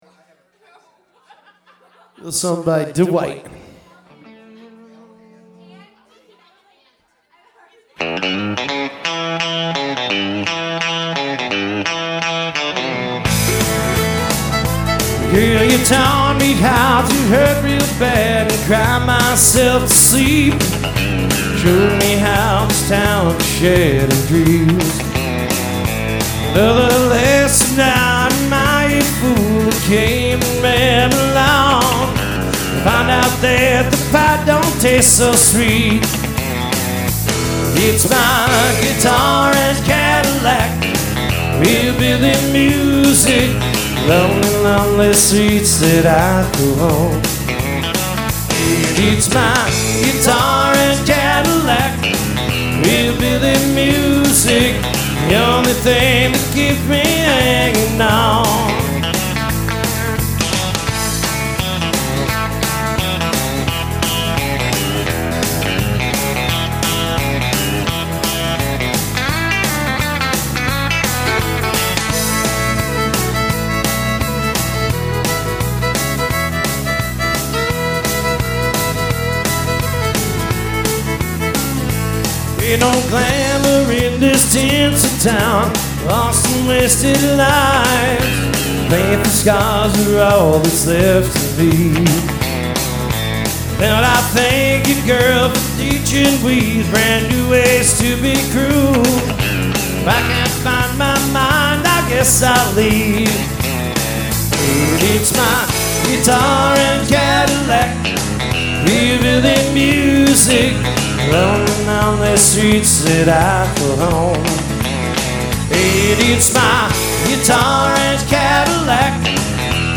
Acoustic/electric guitars and one singer.
(guitar, vocals with backing tracks)